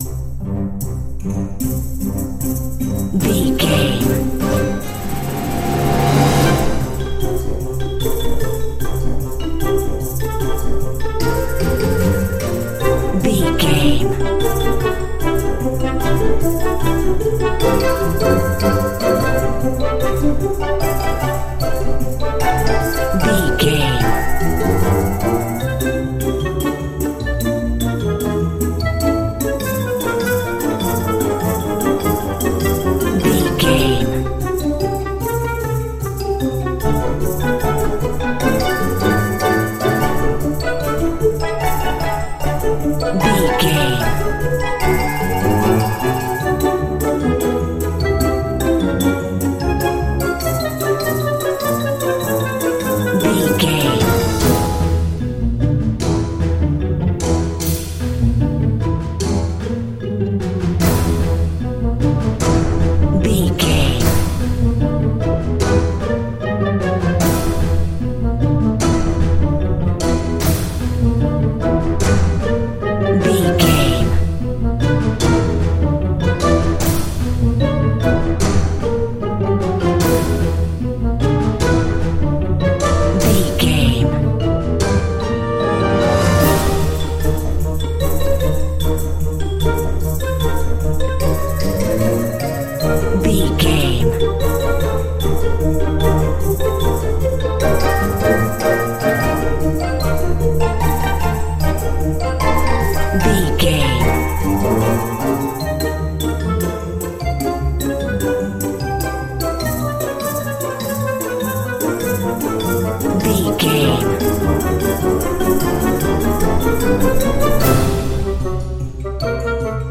Uplifting
Lydian
flute
oboe
strings
orchestra
cello
double bass
percussion
circus
goofy
comical
cheerful
perky
Light hearted
quirky